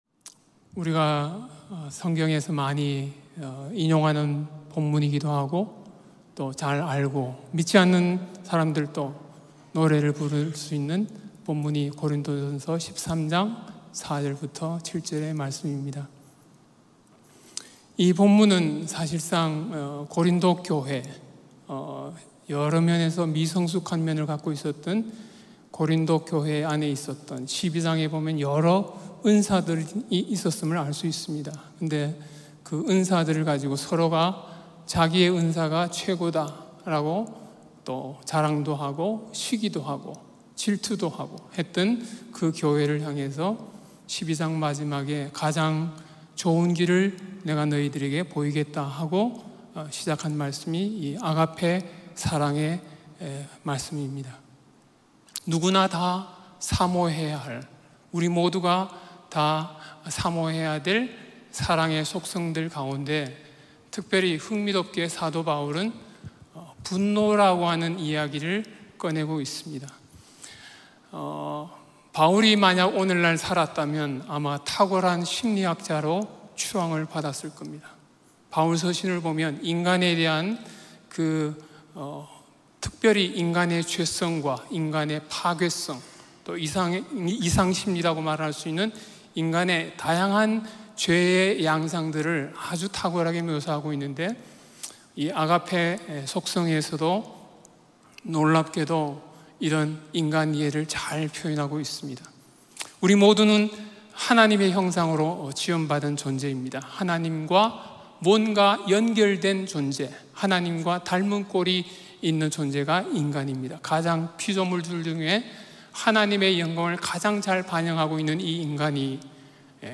예배: 주일 예배